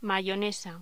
Locución: Mayonesa
voz